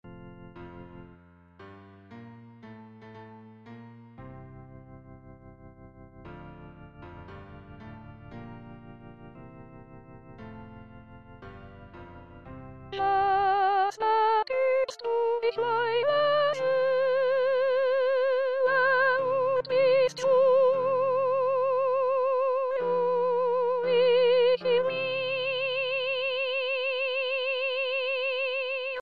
Introduction 5 voix, mes.  1-13